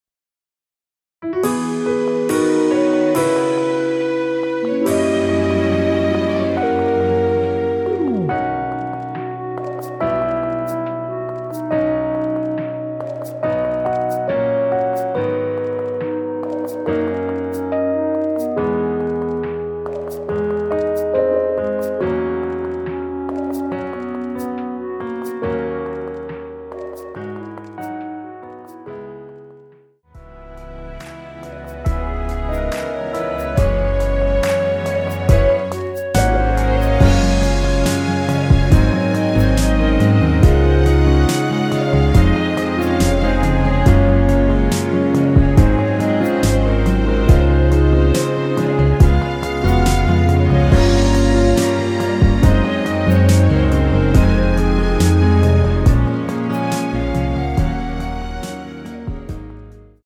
원키에서(-3)내린 멜로디 포함된 MR입니다.
앞부분30초, 뒷부분30초씩 편집해서 올려 드리고 있습니다.